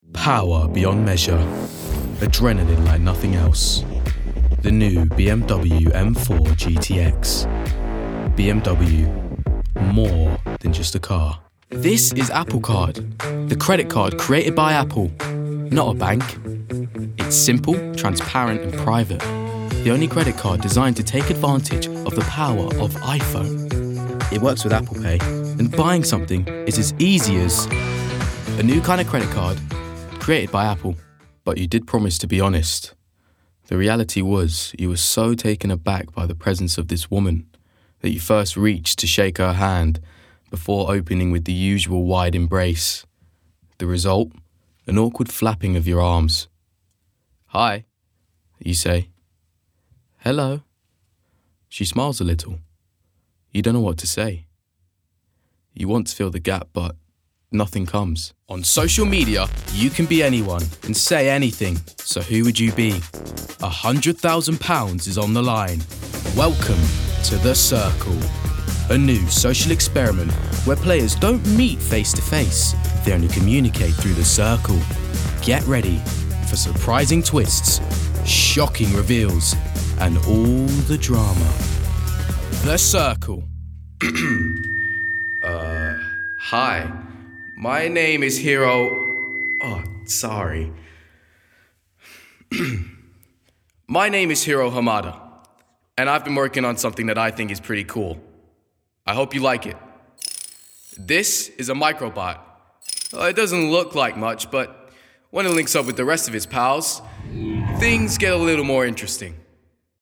Native voice:
South London
Voicereel: